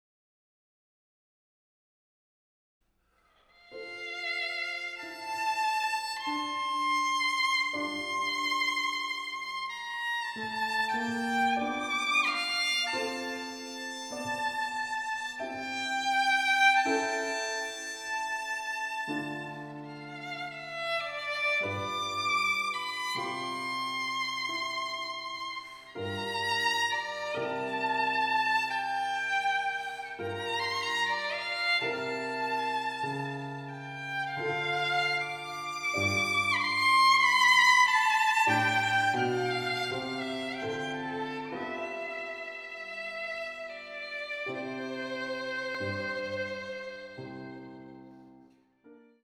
ヴィオラ